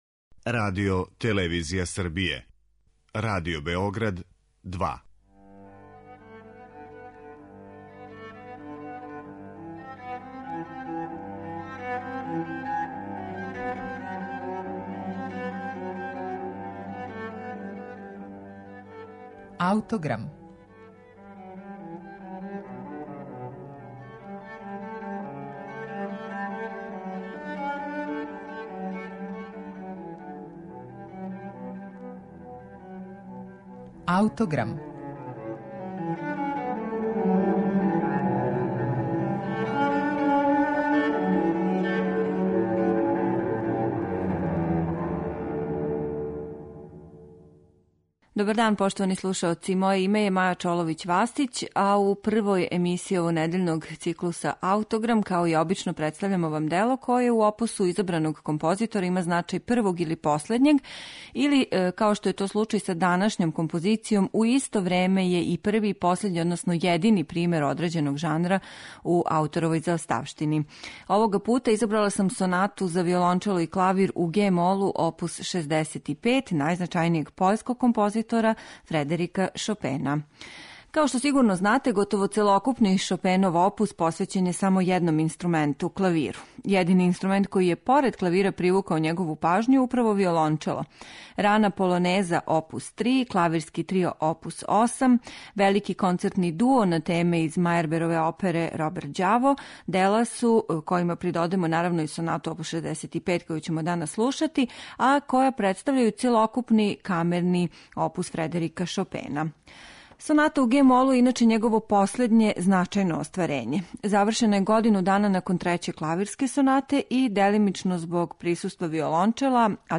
Соната за виолончело и клавир у ге-молу оп. 65
У данашњој емисији имаћете прилику да ову композицију слушате у извођењу врхунских уметница - Наталије Гутман и Елисо Вирсаладзе.